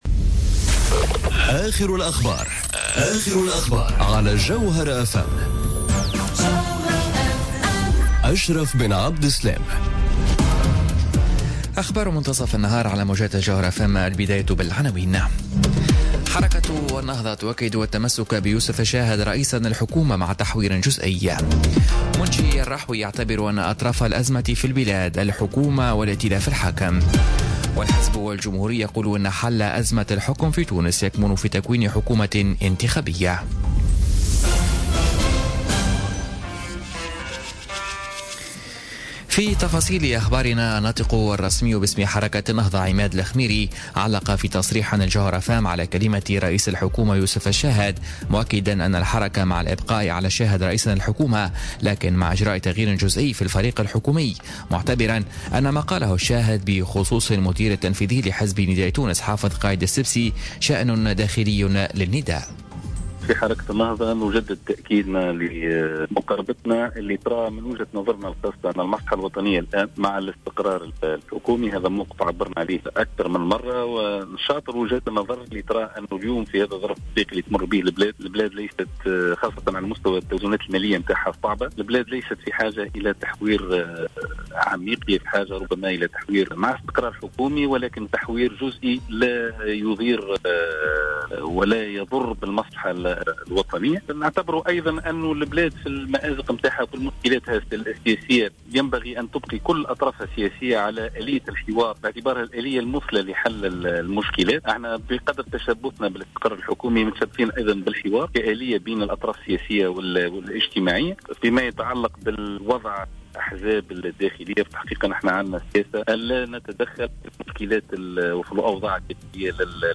نشرة أخبار منتصف النهار ليوم الإربعاء 30 ماي 2018